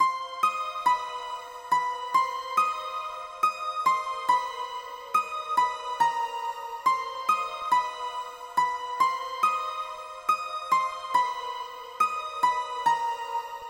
描述：配音dubstep得分动作电影结束环境filmcore黑暗合唱团电子钢琴好莱坞电影
Tag: 好莱坞 电子 钢琴 动作 环境 得分 结束 电影 filmscore 配音 电影配音步 合唱